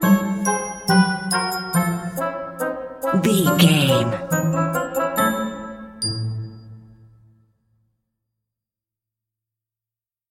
Aeolian/Minor
Slow
percussion
flute
piano
orchestra
double bass
accordion
silly
circus
goofy
comical
cheerful
Light hearted
quirky